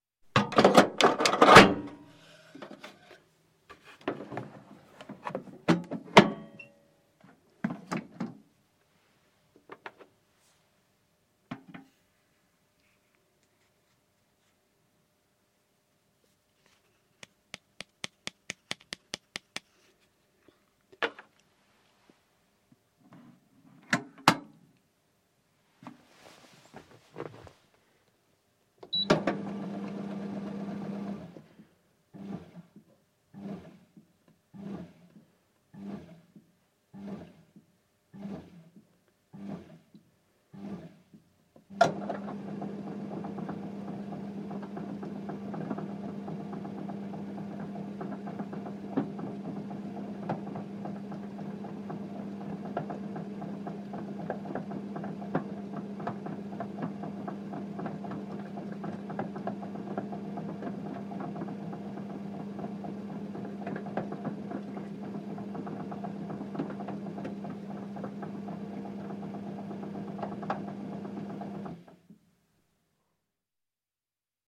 На этой странице собраны звуки хлебопечки — от мерного гула двигателя до сигнала готовности хлеба.
Звук вставки контейнера в хлебопечку и включения режима выпечки